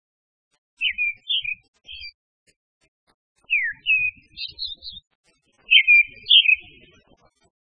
鳥の鳴声その１
〔アカハラ〕クワクワッ／ツィー／キャラランキャラランツリー（さえずり）／（ｷｮﾛﾝ,